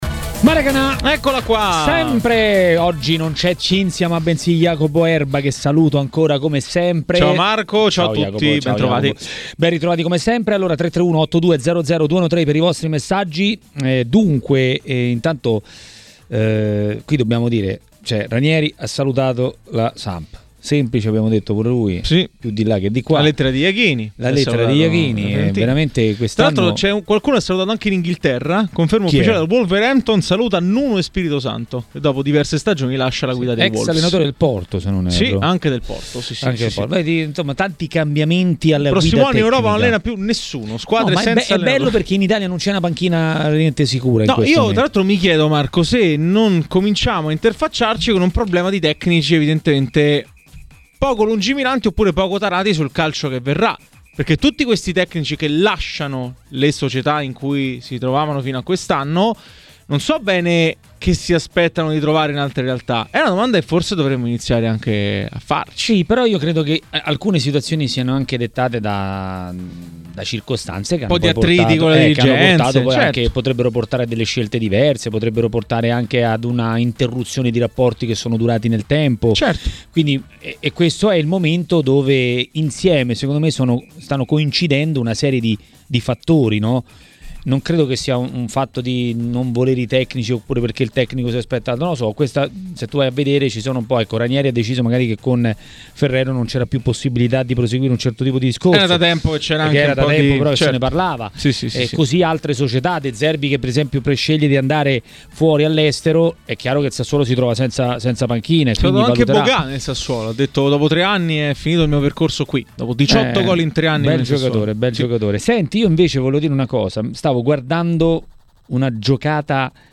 A Maracanà, nel pomeriggio di TMW Radio, è venuto il momento dell'ex calciatore e tecnico Bruno Giordano.